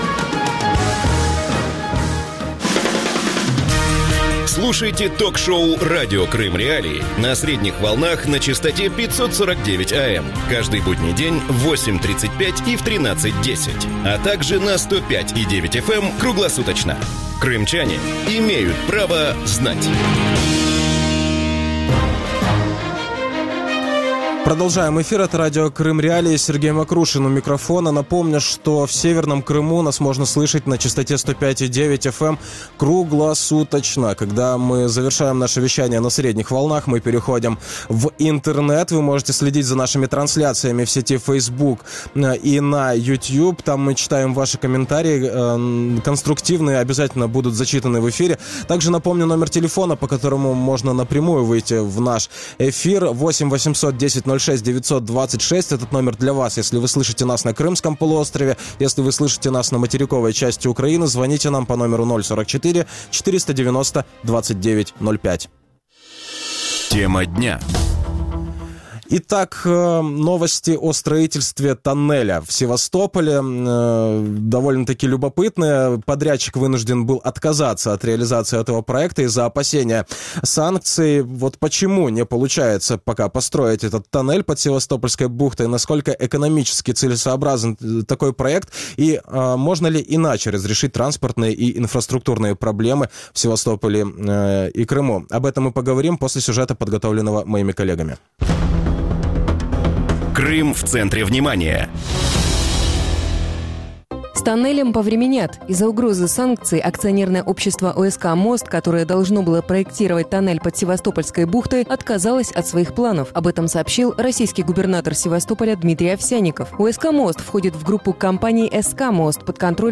Насколько экономически целесообразен такой проект? Как можно разрешить транспортные и инфраструктурные проблемы в Севастополе и Крыму? Гости эфира